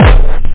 hardtrance.bassdrum+clap.mp3